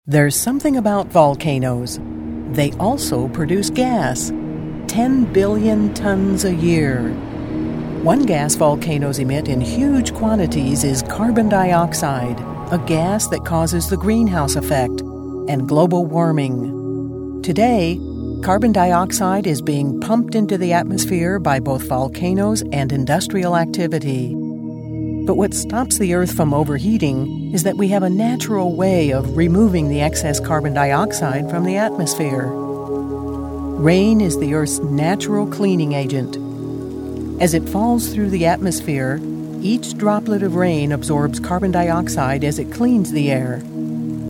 Sennheiser 416 mic, Blue Robbie preamp, Mogami cabling, ProTools 8.04 with various additional plug-ins, MBox mini, Adobe Audition, music and sound fx library.
Smooth, classy, believable.
Sprechprobe: Industrie (Muttersprache):